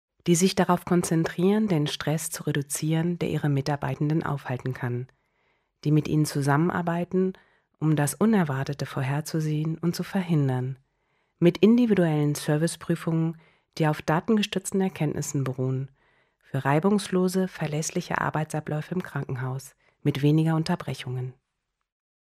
German female voice talent